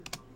Soft Keystroke
fast finger hard key keyboard keystroke soft type sound effect free sound royalty free Memes